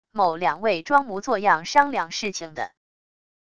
某两位装模作样商量事情的wav音频